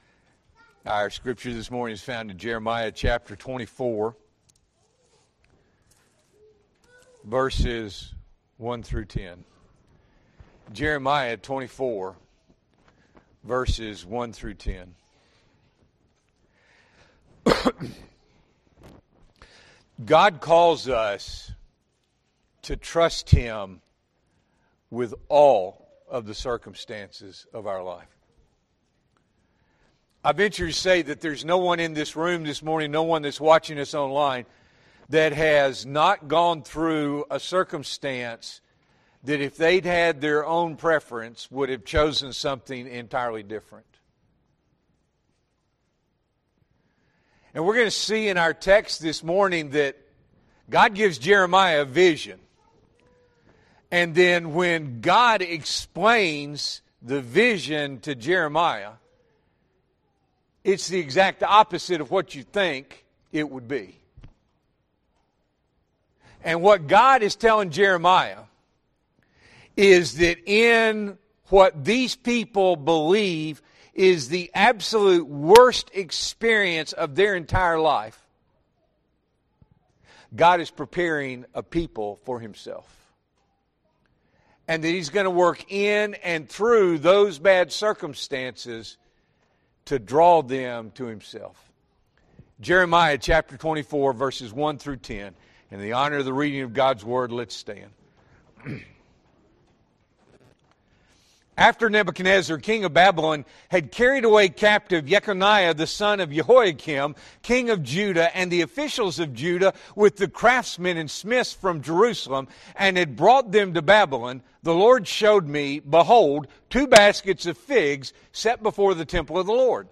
February 16, 2025 – Morning Worship